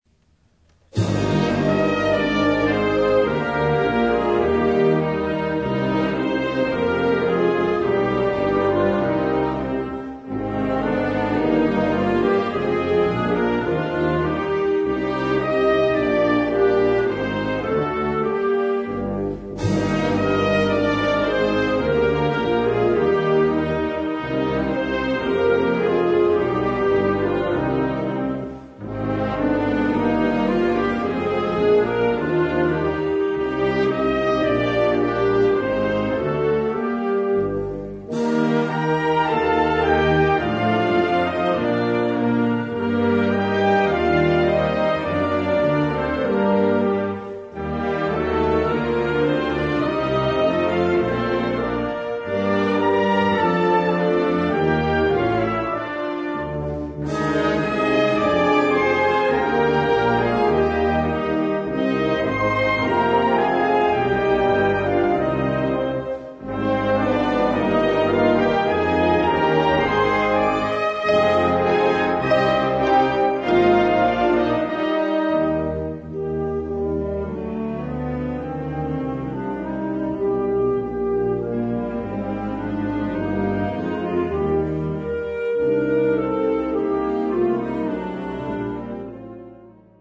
Kategorie Blasorchester/HaFaBra
Unterkategorie Konzertmusik
3 Allegro 2:38